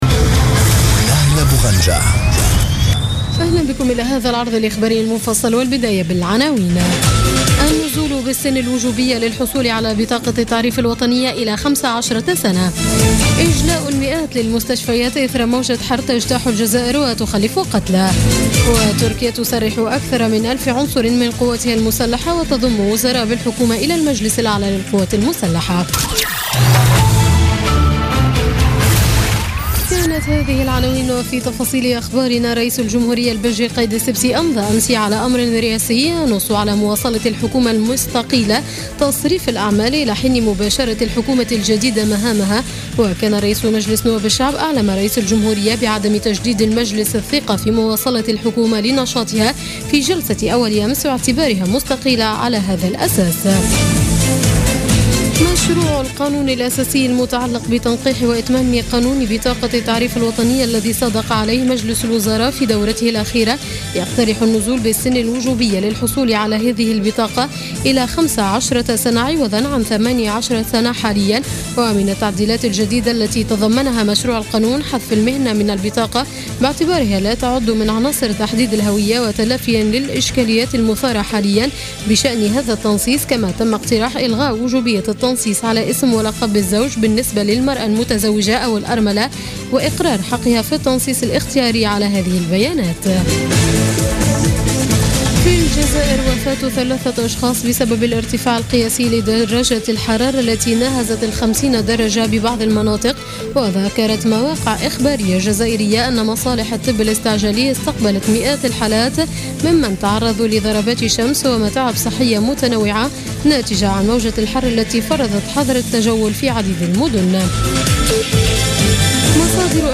Journal Info 00h00 du lundi 1er août 2016